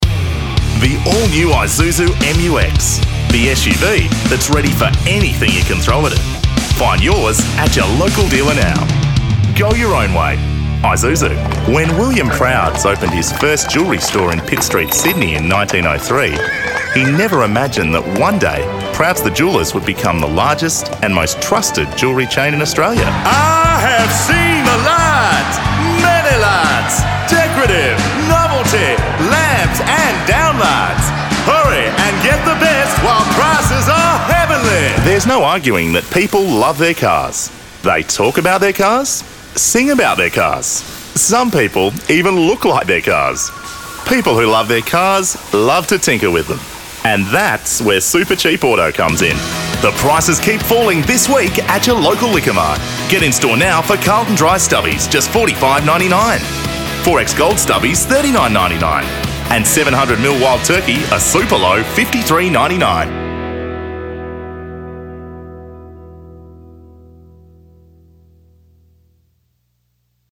Inglés (Australia)
Demo comercial
I have access to a professional radio studio Monday to Friday 10am - 4pm Melbourne Australia time
Adulto joven
Mediana edad
BajoBajoMuy bajo
SeguroAutoritarioCálidoAmistoso